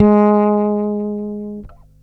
34-G#3.wav